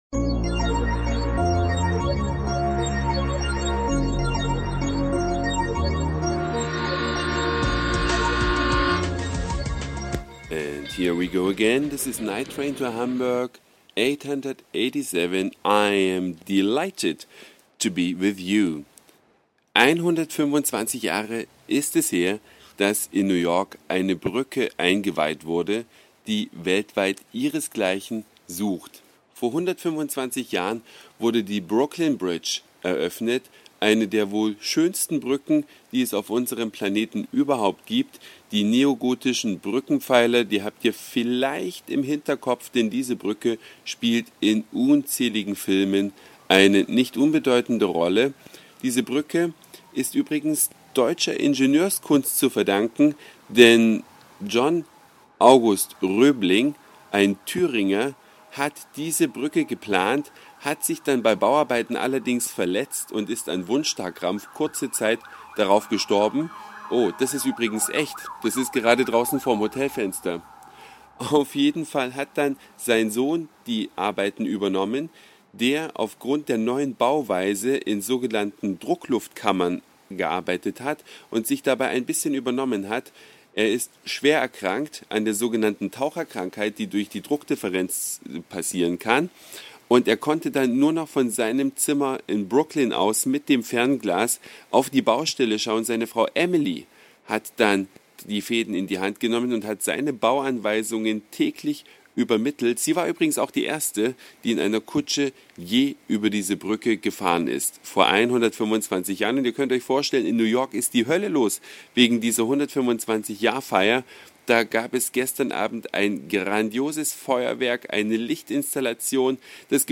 Eine Reise durch die Vielfalt aus Satire, Informationen, Soundseeing und Audioblog.
Undichte Fenster (wie im Podcast zu hören) und spritsaufende